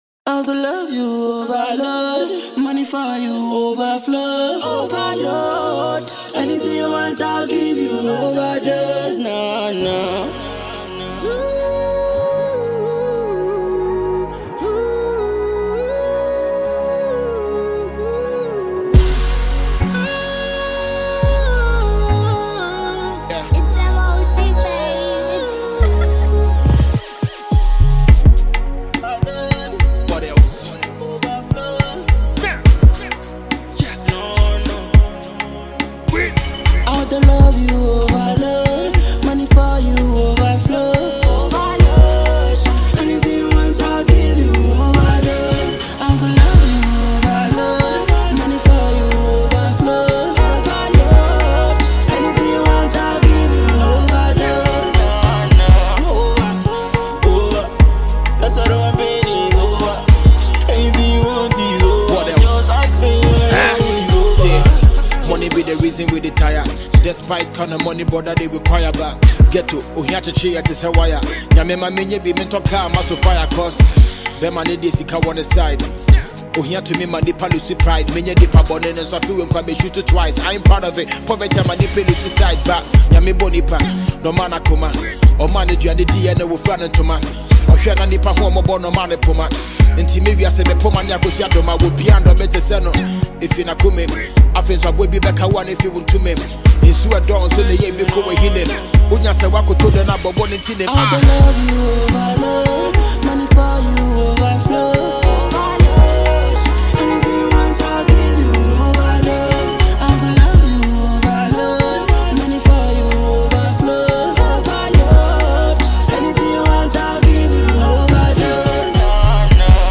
The love song